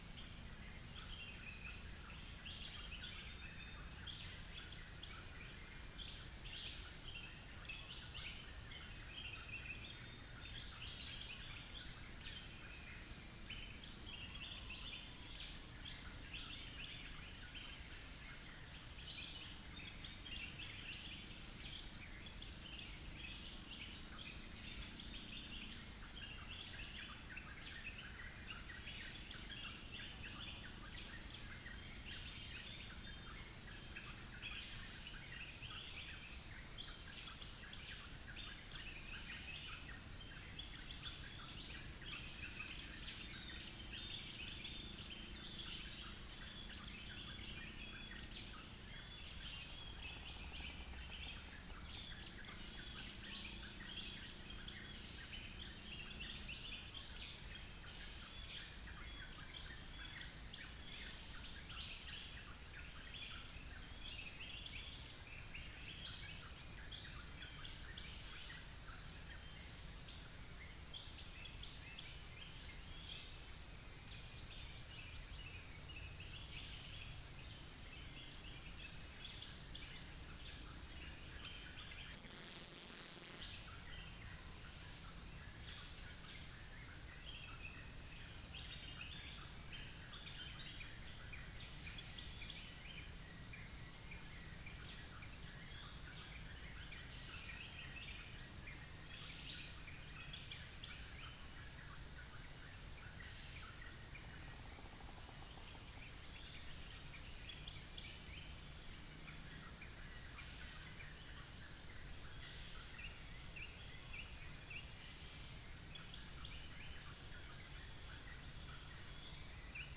Upland plots dry season 2013
Orthotomus sericeus
Pycnonotus goiavier
Malacopteron magnirostre
Pellorneum nigrocapitatum